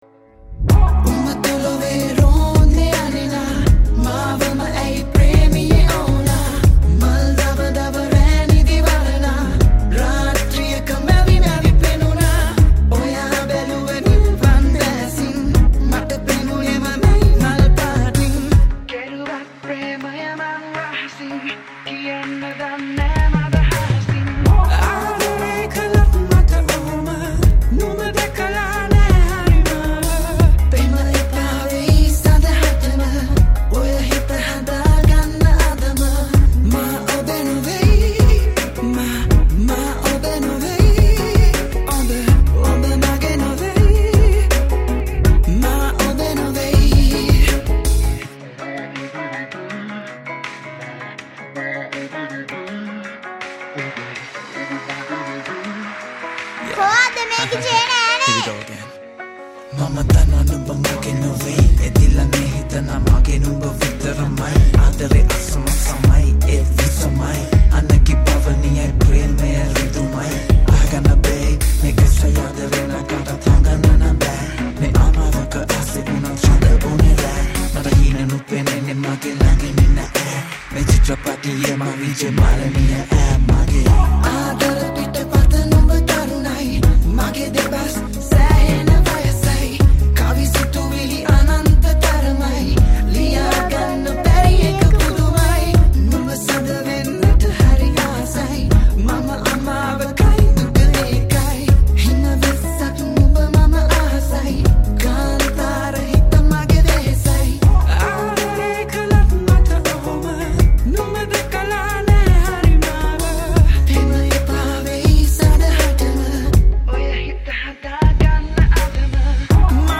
OST